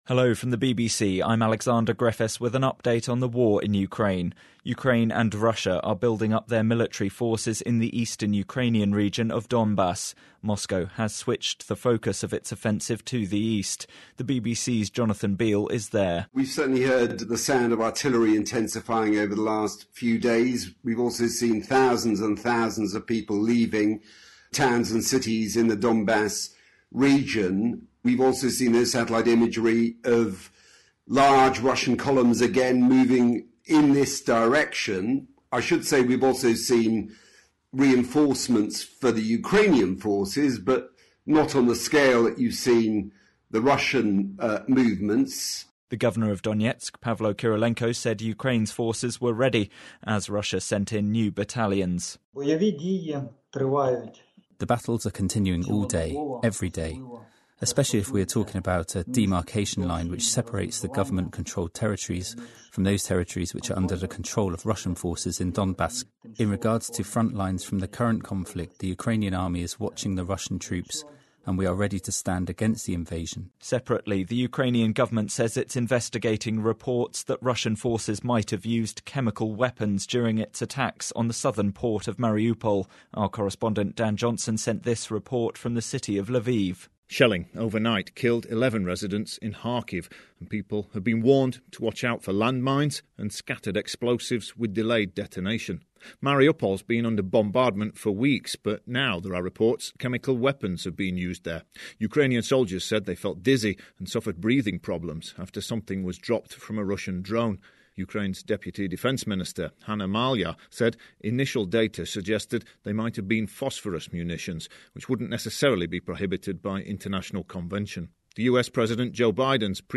Here's your update from the BBC…